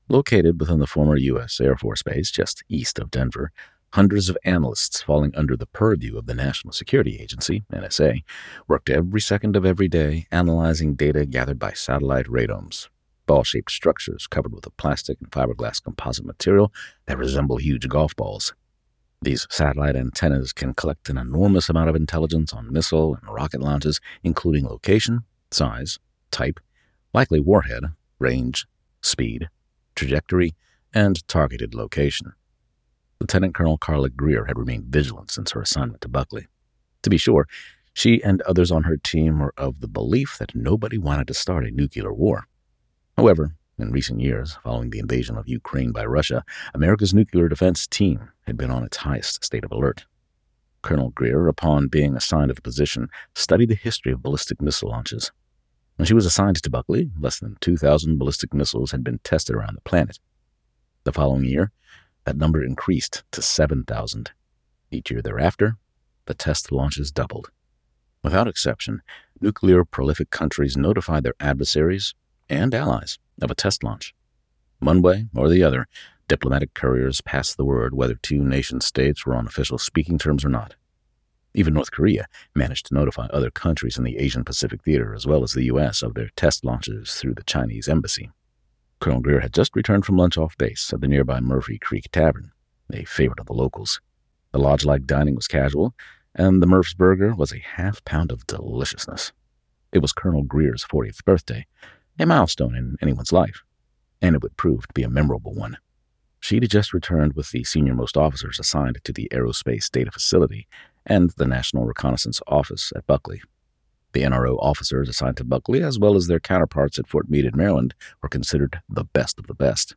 Narrator with a strong, confident and friendly voice to tell your story.
Auduobooks
In my home studio, I have narrated almost 300 books as myself and under my romance pseudonym.